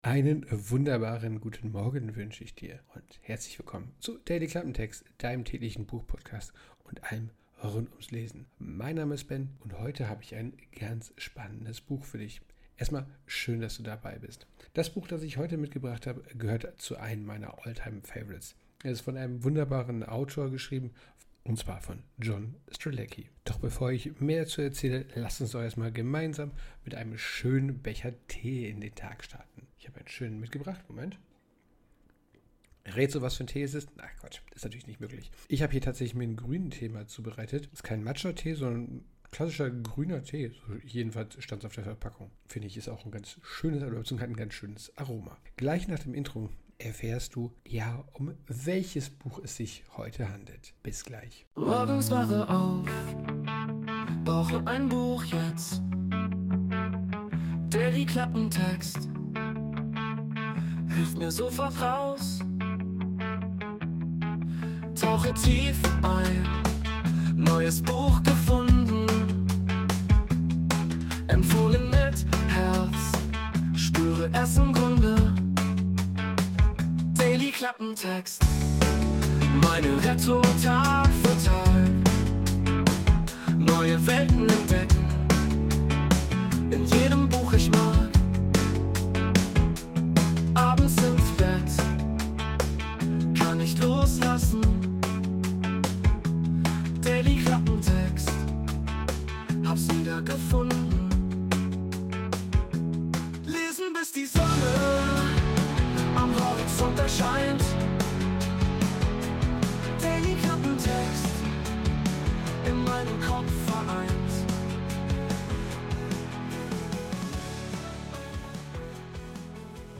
Instrumental Melody
Intromusik: Wurde mit der KI Suno erstellt.